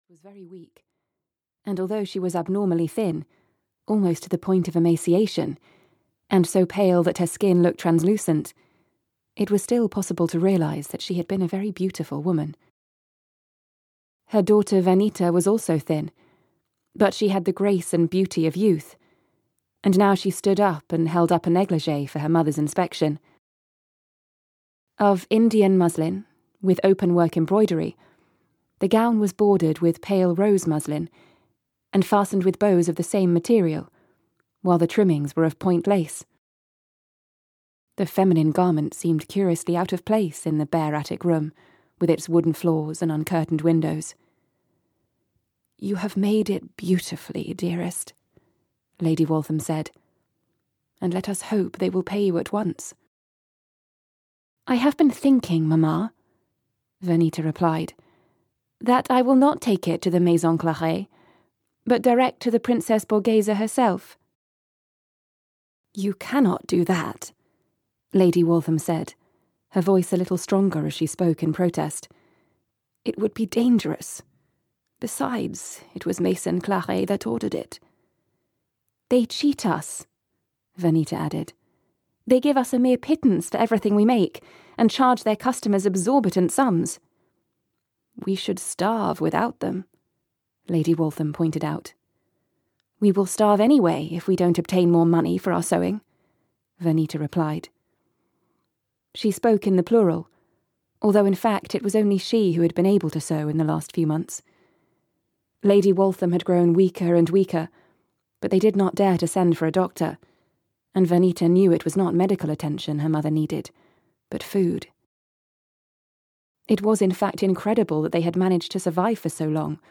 No Escape from Love (EN) audiokniha
Ukázka z knihy